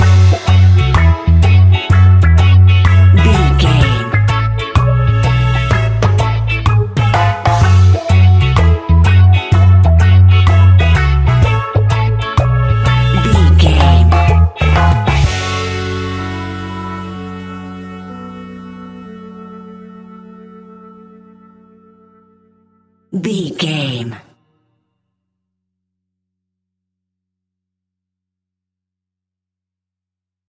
Classic reggae music with that skank bounce reggae feeling.
Uplifting
Aeolian/Minor
F#
laid back
chilled
off beat
drums
skank guitar
hammond organ
percussion
horns